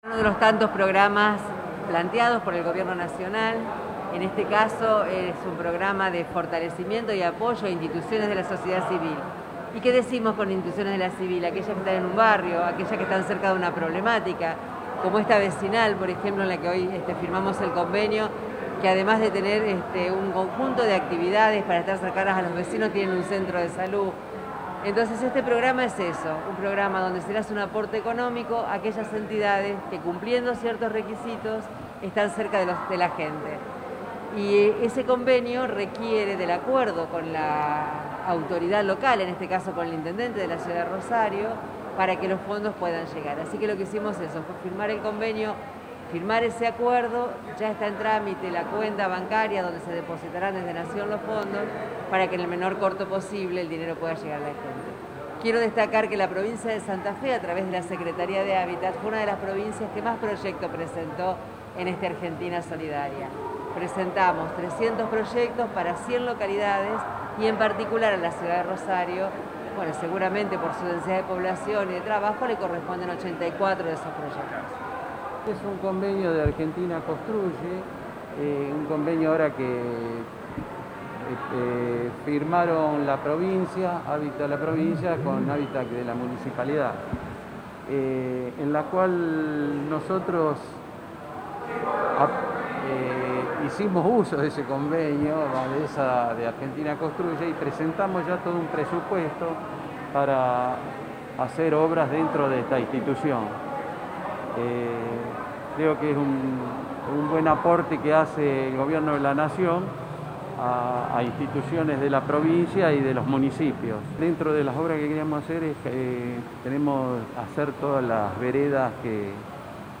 Declaraciones de las autoridades.